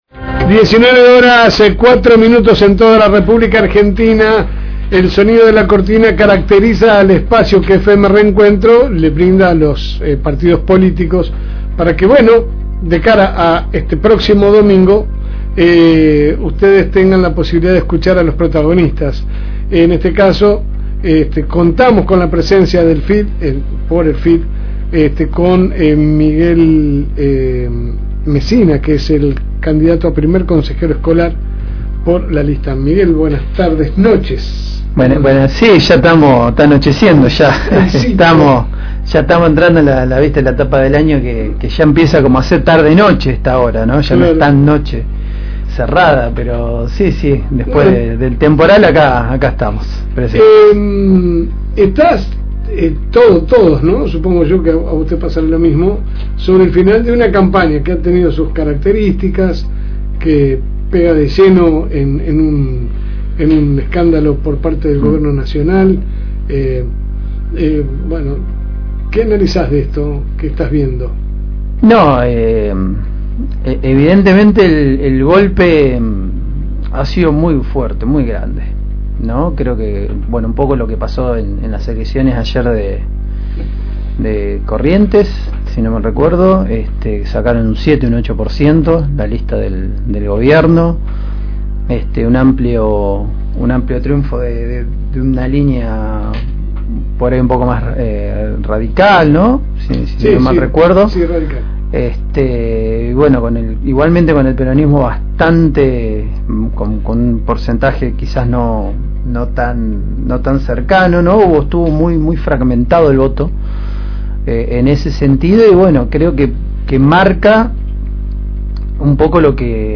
Espacio Electoral.